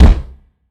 Kicks
CC - Rare Kicker.wav